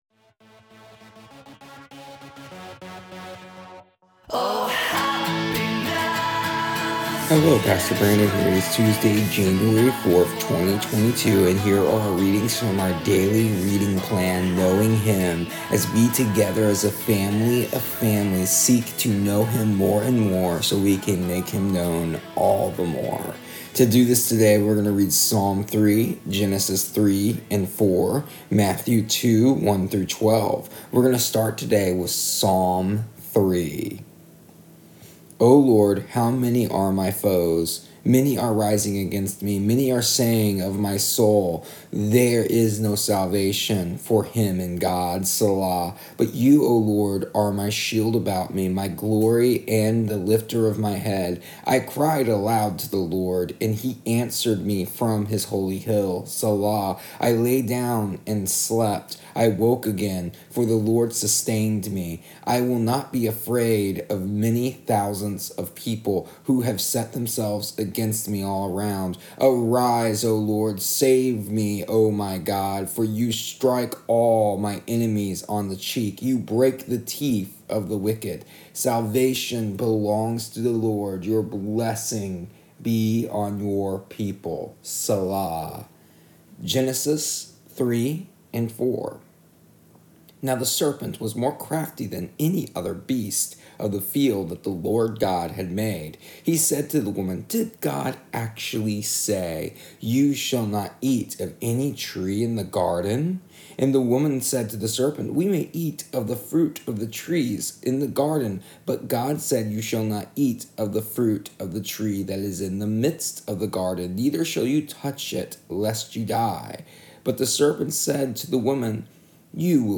Here is the audio version of our daily readings from our daily reading plan Knowing Him for January 4th, 2022.